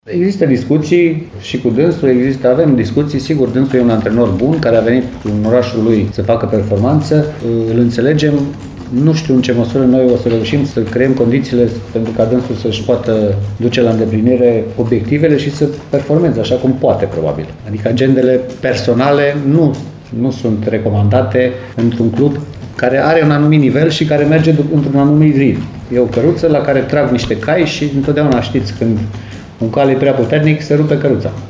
Divizionara secundă Ripensia Timișoara a susținut astăzi o conferință de presă la noul sediu al clubului, situat în Piața Libertății, în clădirea „Primăriei Vechi”, în care a realizat un bilanț al activității sportive și administrative din acest sezon și a vorbit despre perspective.